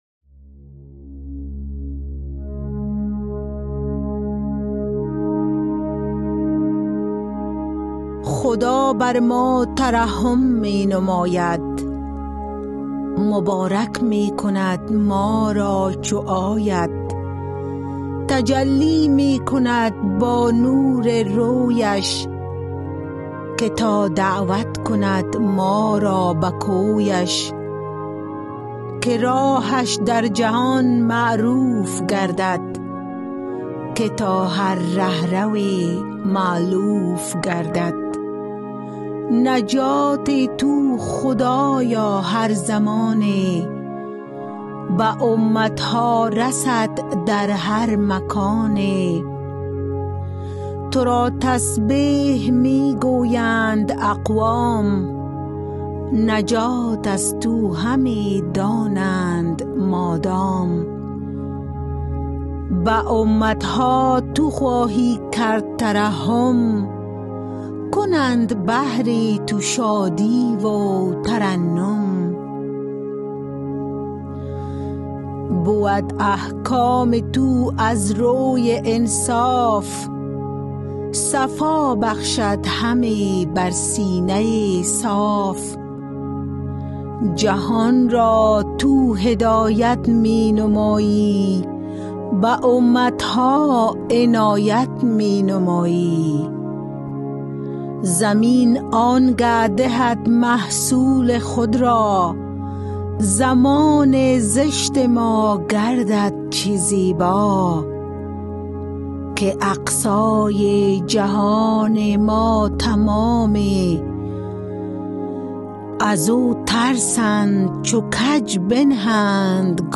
Poem Psalm 67